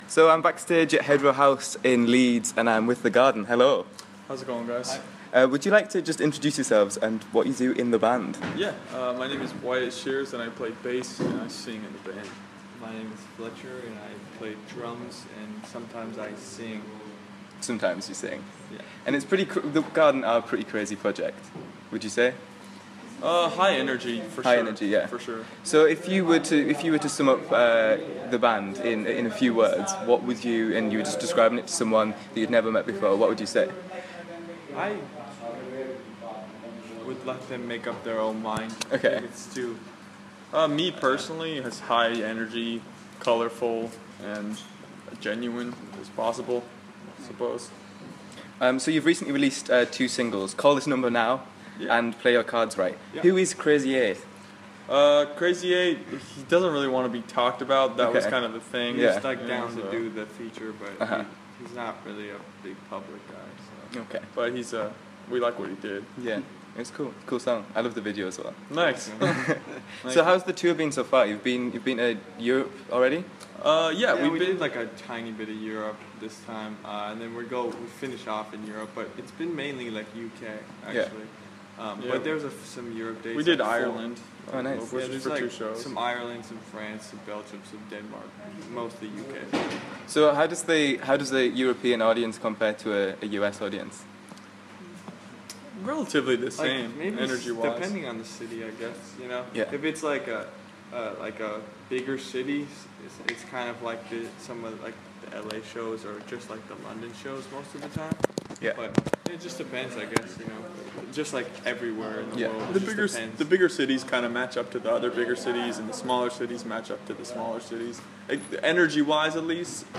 The Garden Interview